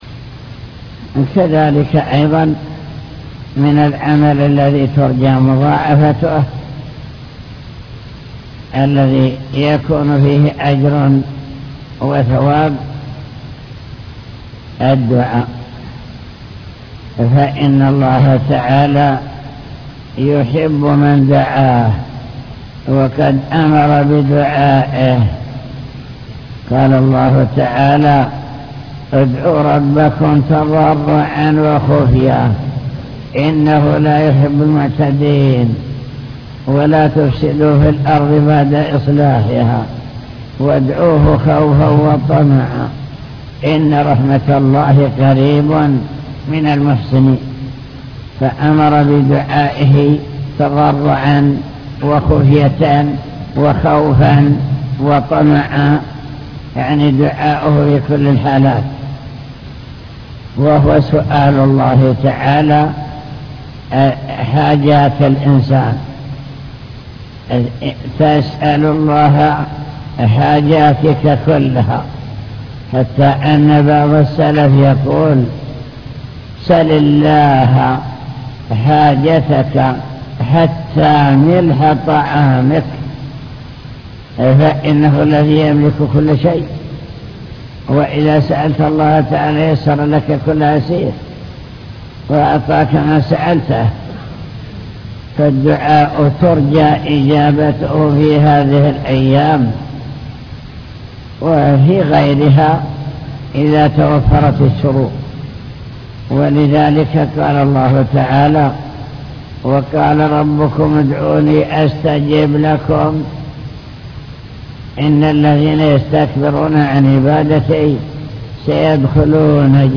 المكتبة الصوتية  تسجيلات - لقاءات  اللقاء المفتوح